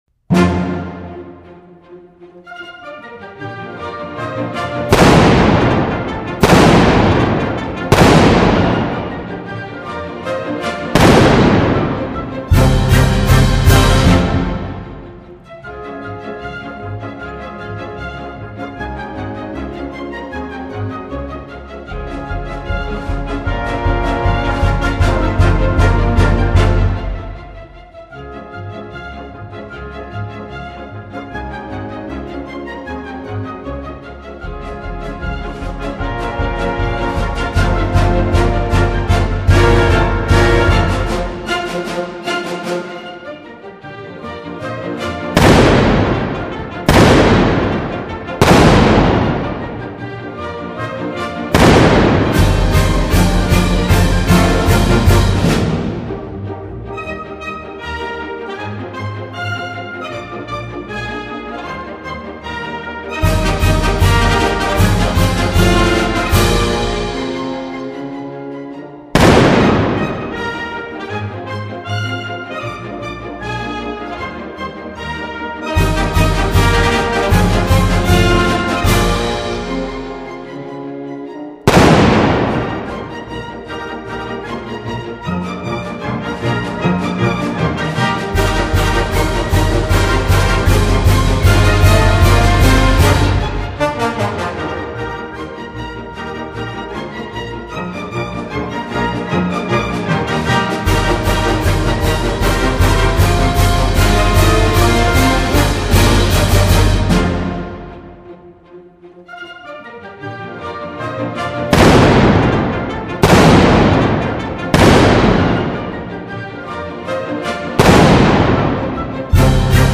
靓绝声色，器乐饱满，频段动态通透玲珑，
包括所有特殊环境声效，每一瞬间细节无不原声毕现，
每一曲作品精湛处还原至逼真无遗。